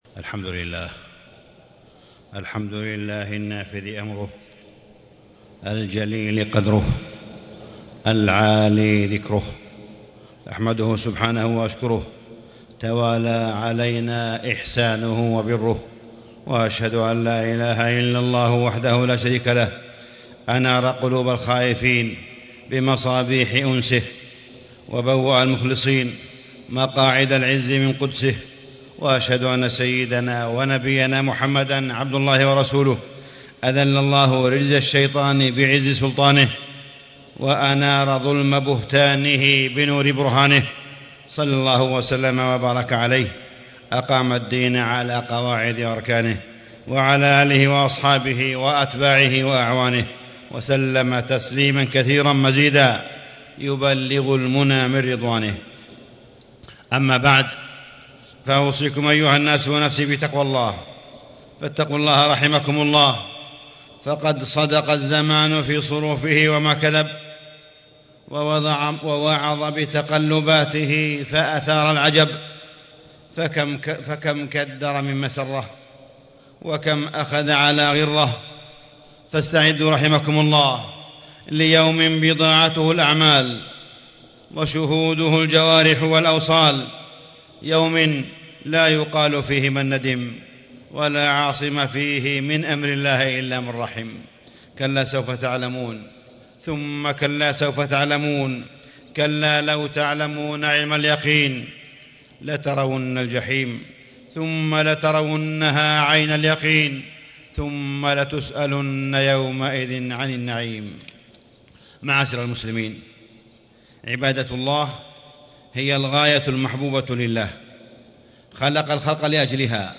تاريخ النشر ١٨ ربيع الأول ١٤٣٦ هـ المكان: المسجد الحرام الشيخ: معالي الشيخ أ.د. صالح بن عبدالله بن حميد معالي الشيخ أ.د. صالح بن عبدالله بن حميد الصلاة قرة عيون الموحدين